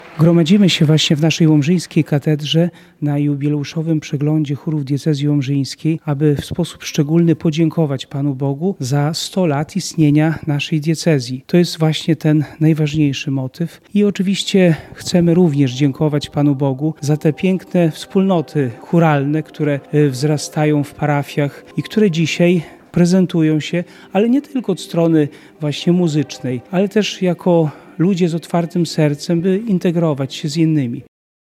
280 osób z 18 chórów z całej diecezji zaprezentowało się w sobotę (22.11) w łomżyńskiej Katedrze podczas Jubileuszowego Przeglądu Chórów Diecezji Łomżyńskiej.